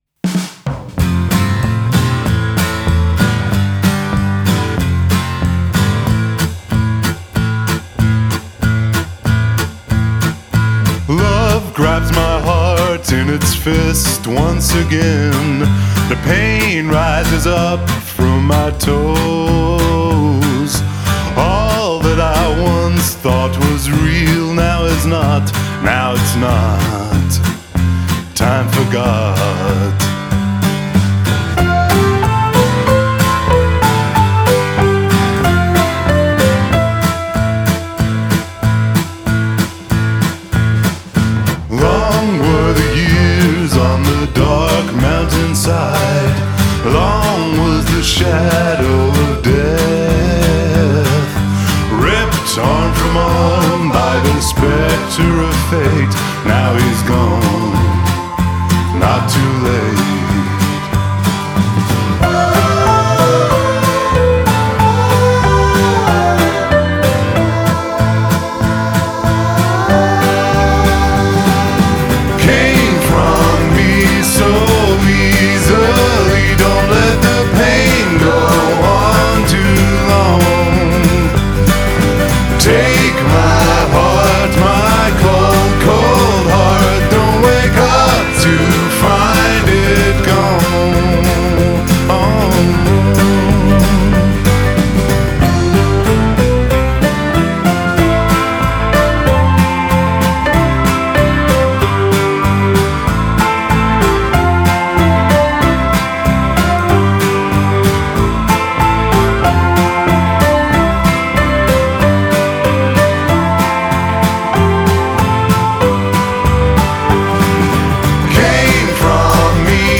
being a baritone